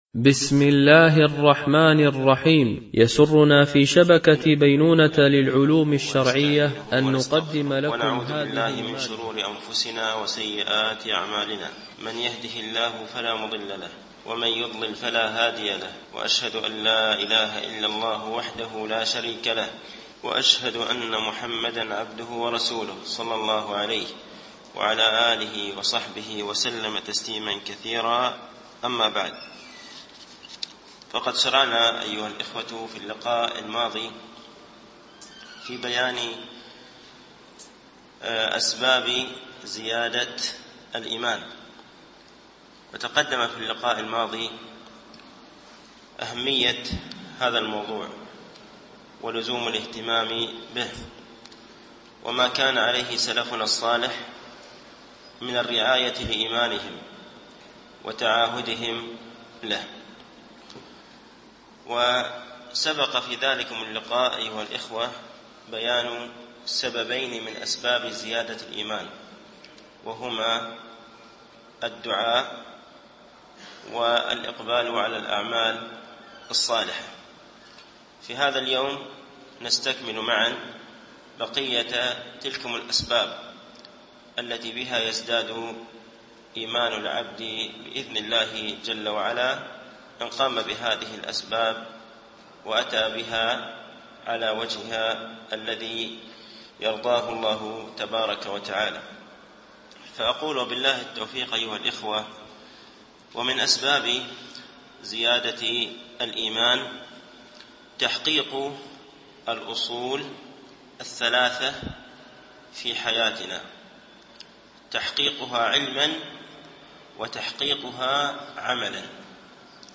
شرح مقدمة ابن أبي زيد القيرواني ـ الدرس الحادي و الستون